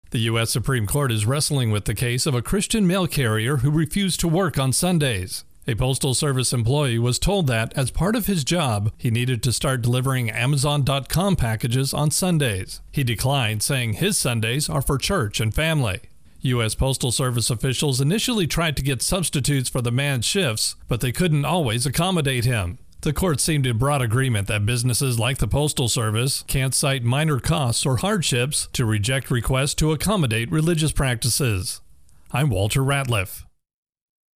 Intro and voicer for Supreme Court Post Office.